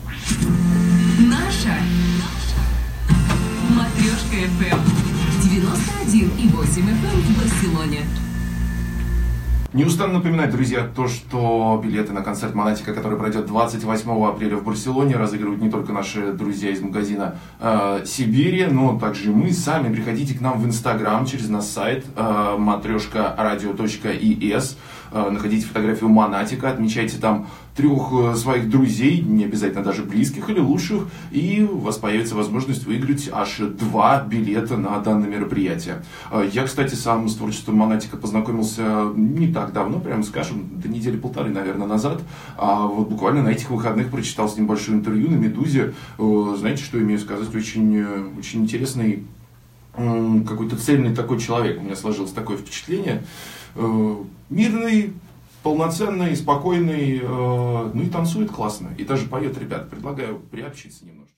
Identificació i xarxes socials de la ràdio